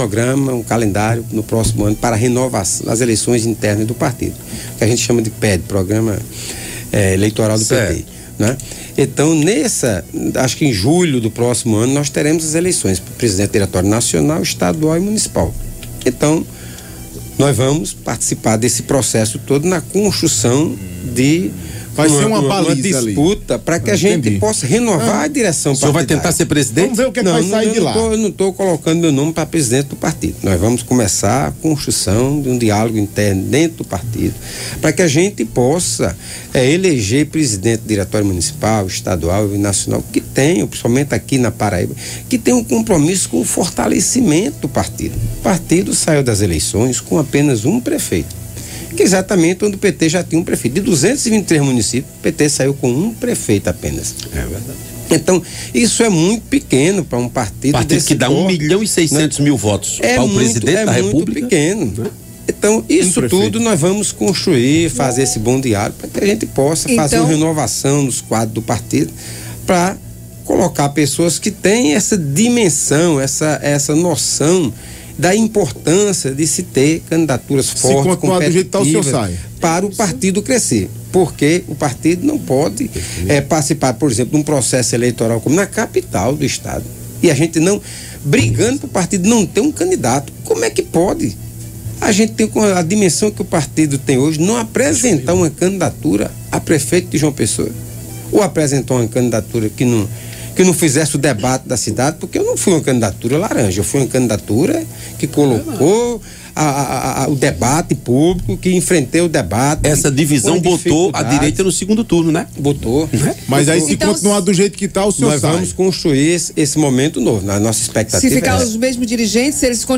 O deputado estadual Luciano Cartaxo (PT) concedeu uma entrevista a um programa de rádio, na tarde desta terça-feira (10), onde abordou sua permanência no partido e a falta de apoio das lideranças partidárias na última eleição. Cartaxo destacou a importância das eleições internas do partido, previstas para julho do próximo ano, e a necessidade de renovação da direção partidária.